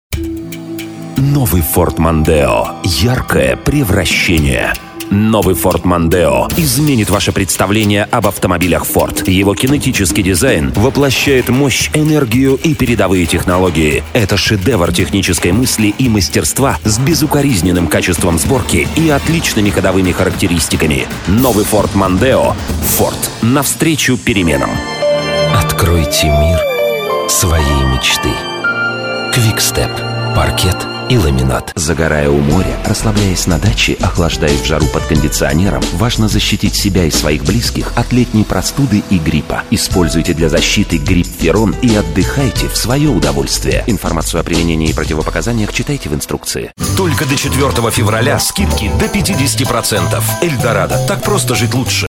Диктор федерального уровня. Один из самых известных актеров дубляжа.